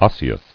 [os·se·ous]